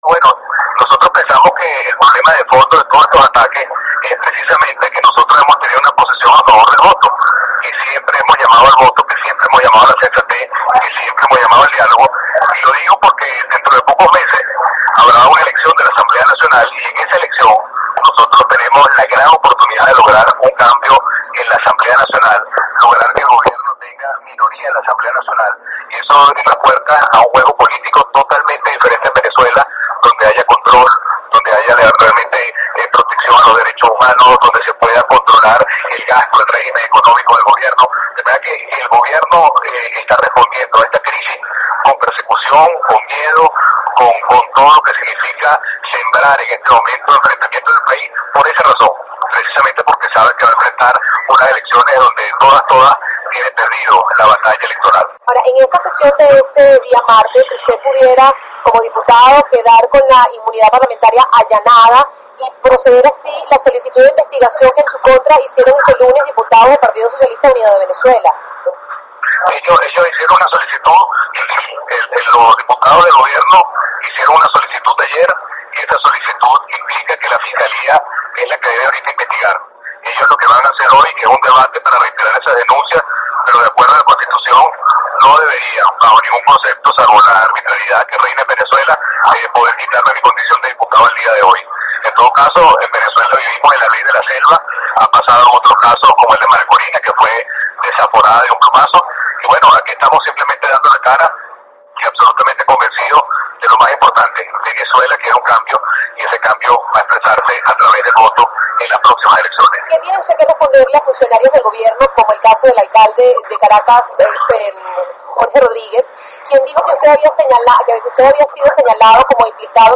Entrevista con Julio Borges